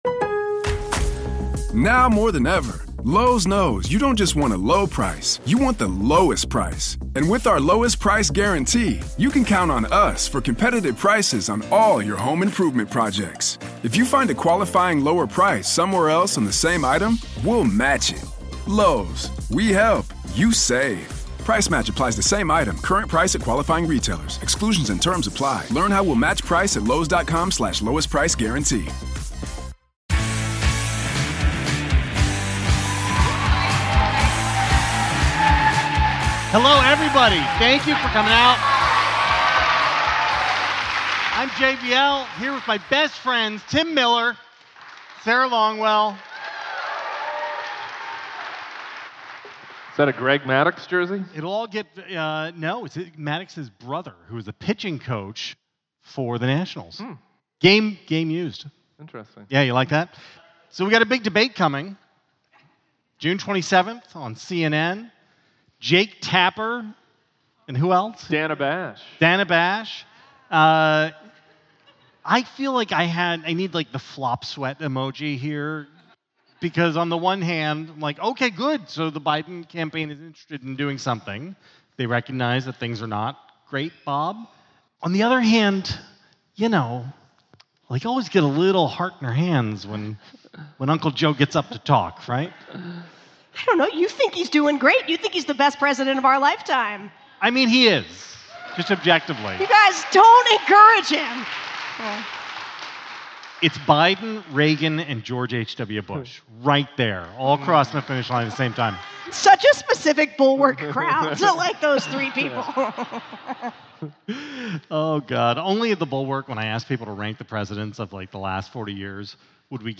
The gang was live in D.C. last night to talk President Biden challenging Donald Trump to a debate in June. The former president accepted the early debate, but is it a smart move from the White House?